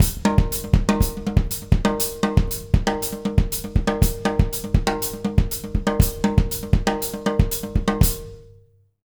120SALSA05-L.wav